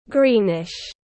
Greenish /ˈɡriː.nɪʃ/